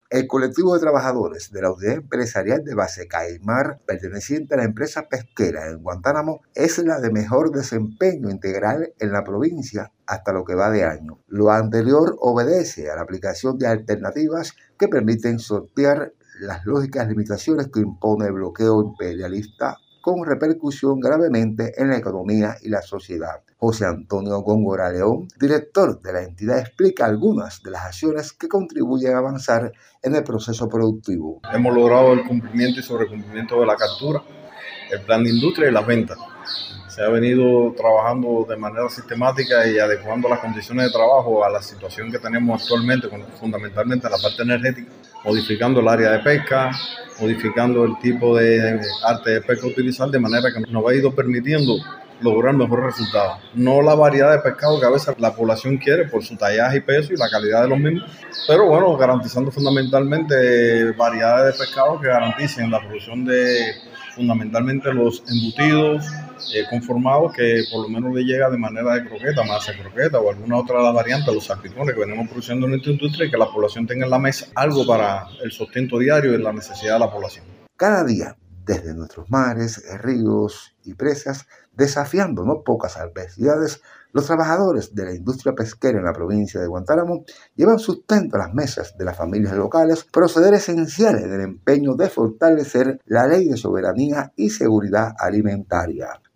Saltar al contenido 22 de abril de 2026 Radio Guantánamo Trinchera Antimperialista NOTICIAS Economía Salud Deportes Cultura Internacionales Guantánamo CMKS ¿QUIENES SOMOS?